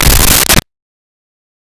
Creature Foot Step 01
Creature Foot Step 01.wav